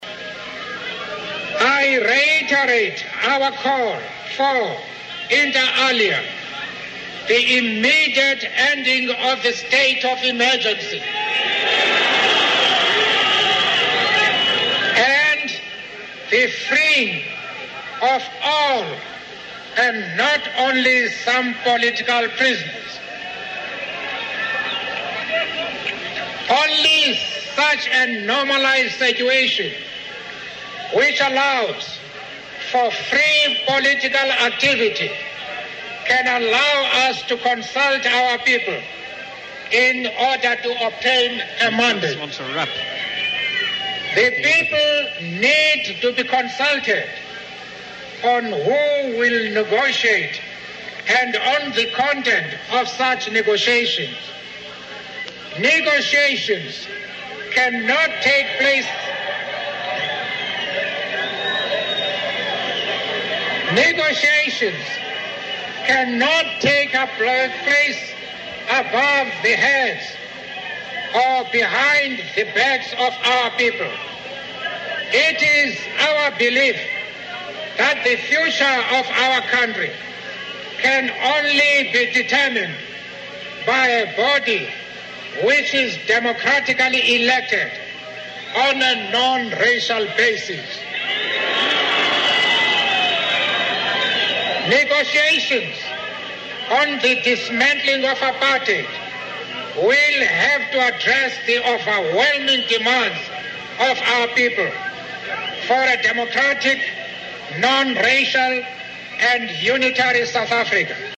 在线英语听力室名人励志英语演讲 第36期:为理想我愿献出生命(10)的听力文件下载,《名人励志英语演讲》收录了19篇英语演讲，演讲者来自政治、经济、文化等各个领域，分别为国家领袖、政治人物、商界精英、作家记者和娱乐名人，内容附带音频和中英双语字幕。